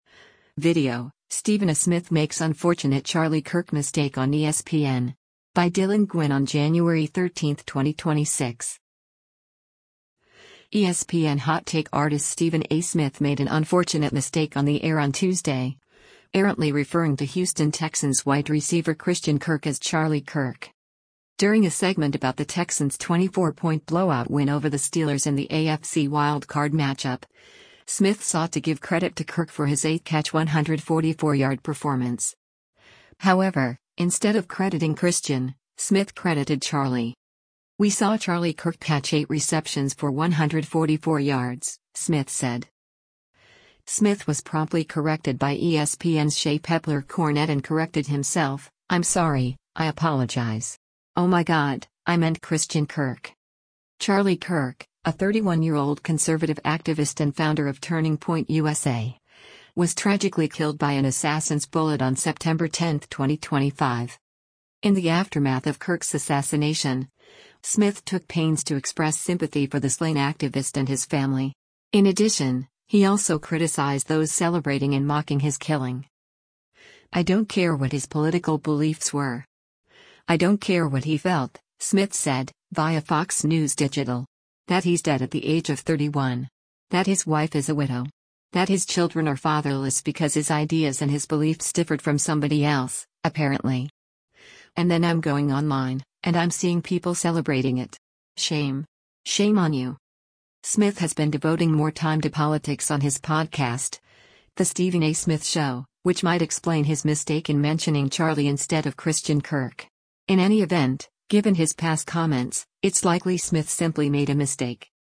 ESPN hot-take artist Stephen A. Smith made an unfortunate mistake on the air on Tuesday, errantly referring to Houston Texans wide receiver Christian Kirk as Charlie Kirk.